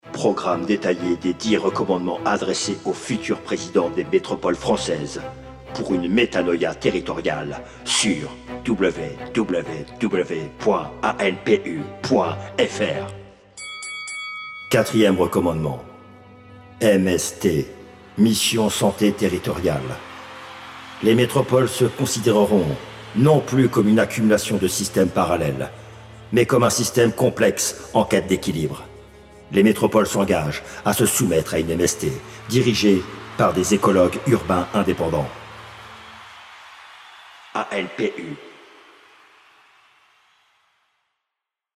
Jingle 3ème recommandement